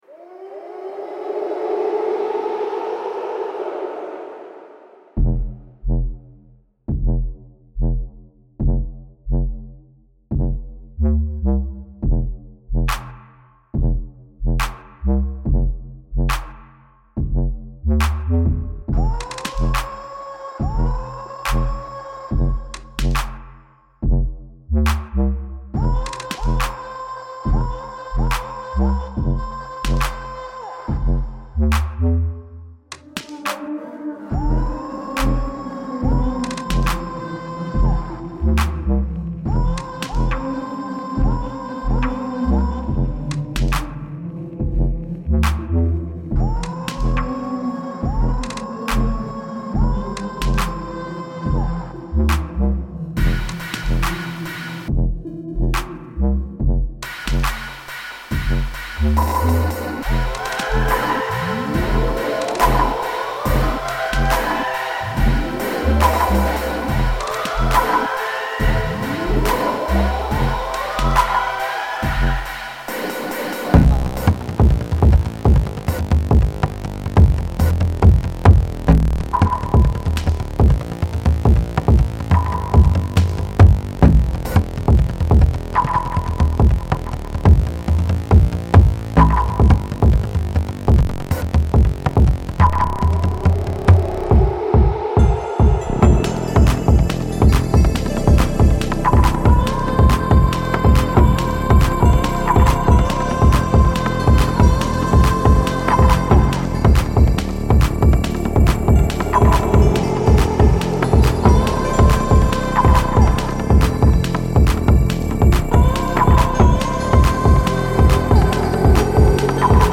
Reimagined sound from Jerusalem, Israel